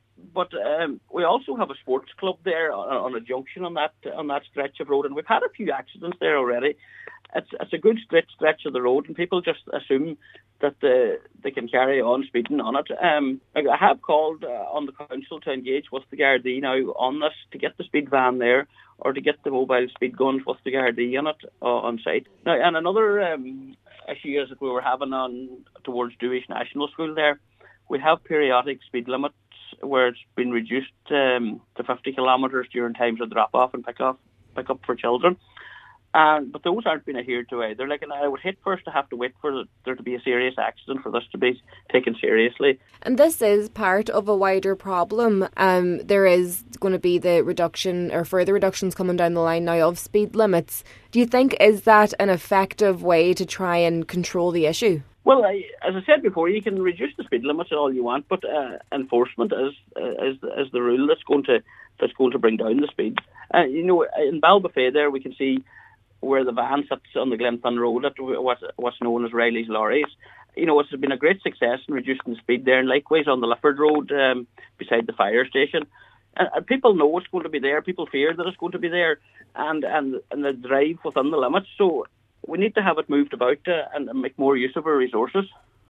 Cllr Scanlon also says a speed van should be placed near Dooish National School to improve safety in the area: